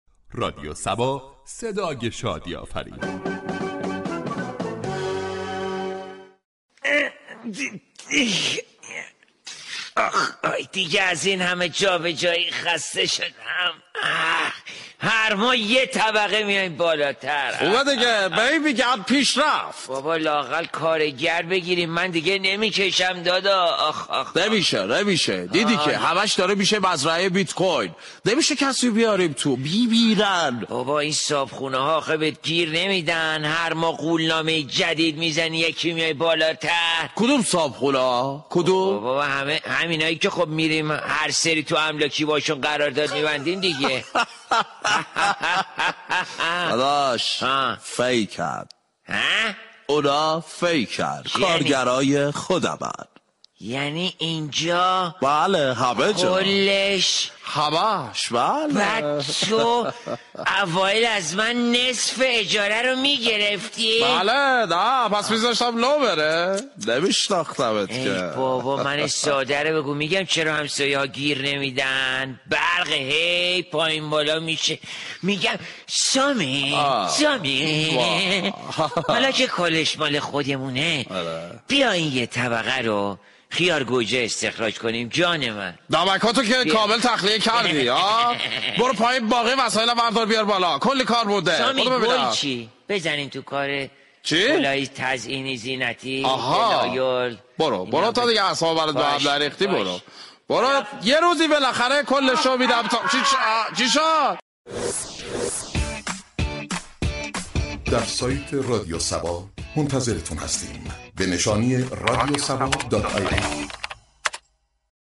شهر فرنگ در بخش نمایشی با بیان طنز به موضوع "استخراج بیت كوین و ارزهای دیجیتال "پرداخته است ،در ادامه شنونده این بخش باشید.